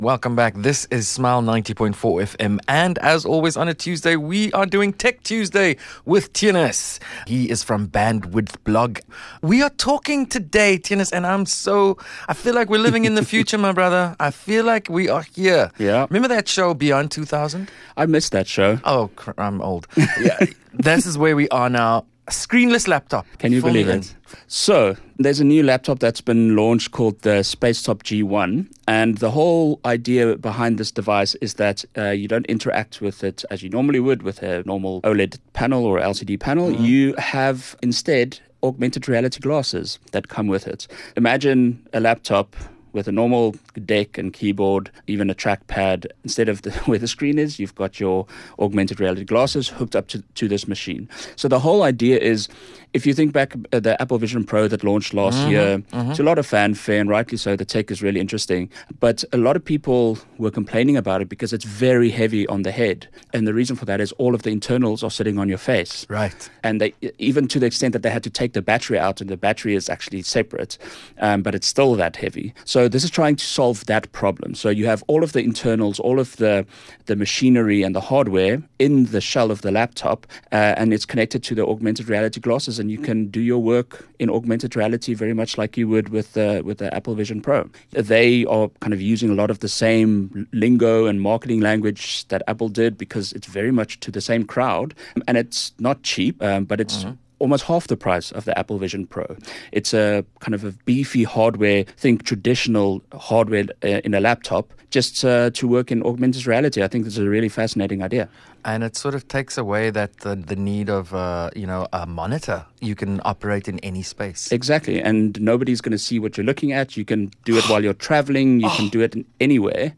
Tech Talk